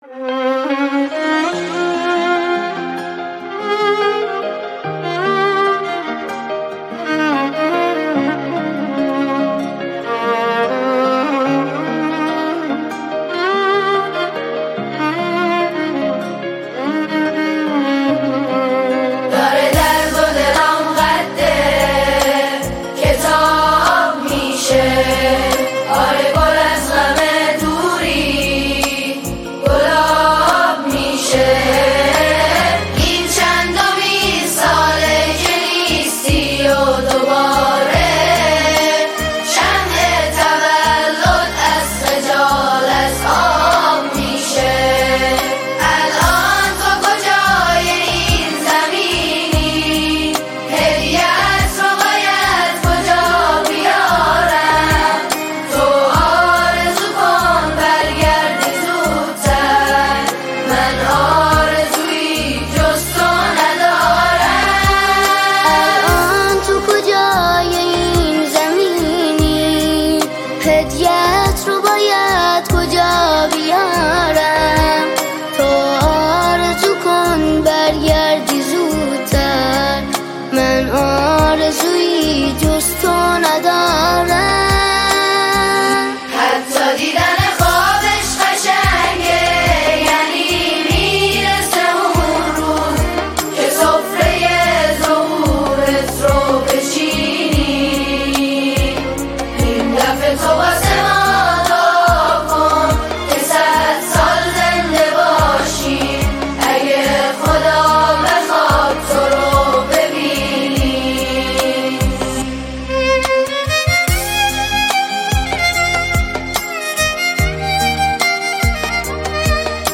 دلنشین و احساسی